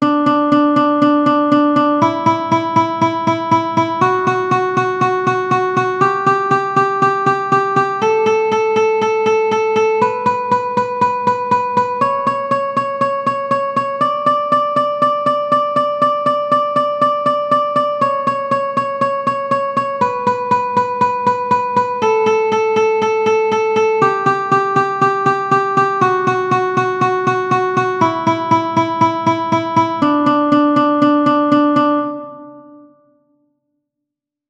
Single String Strumming
I put it in quotes because it could also be thought of as alternate flat picking when you are ‘strumming’ just one string. The goal is to gain control over the strings that you play and the ones that you don’t. In this case, you will want to focus on just the highest ‘D’ string.